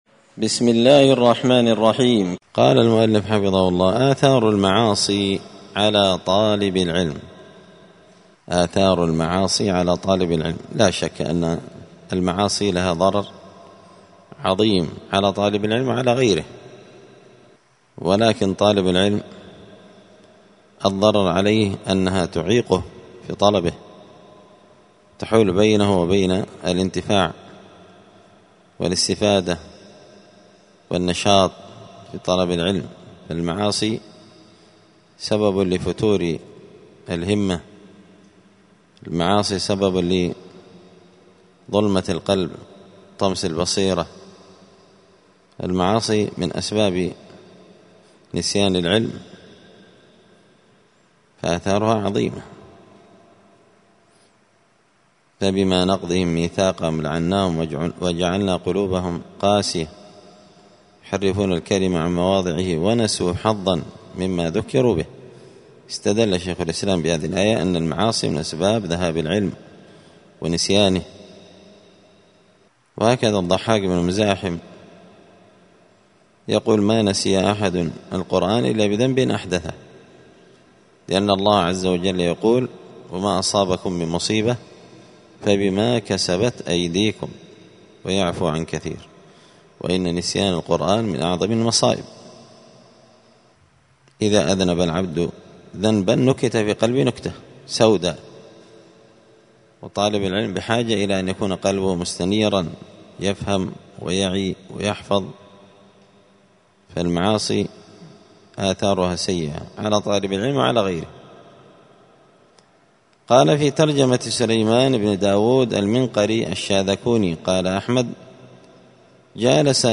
دار الحديث السلفية بمسجد الفرقان بقشن المهرة اليمن
*الدرس الحادي والثلاثون (31) آثار المعاصي على طالب العلم*